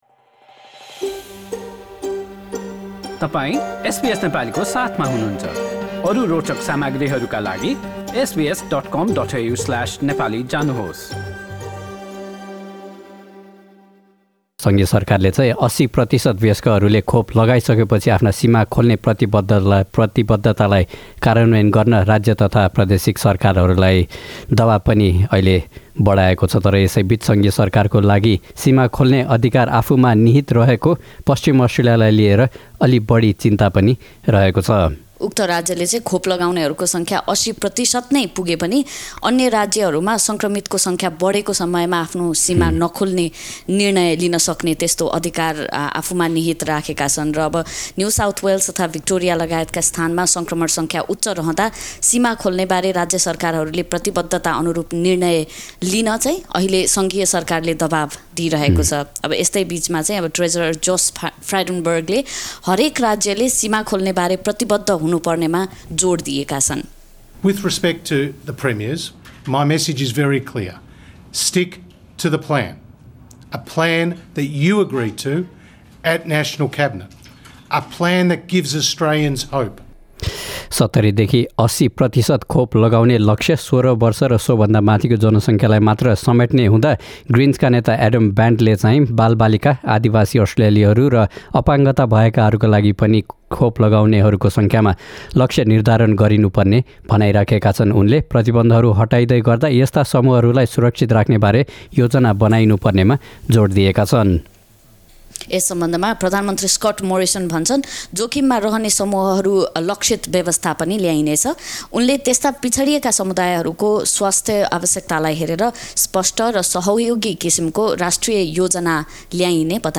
नेताहरूको भनाई सहितको पुरा रिपोर्ट सुन्नुहोस्: null हाम्रा थप अडियो प्रस्तुतिहरू पोडकास्टका रूपमा उपलब्ध छन्।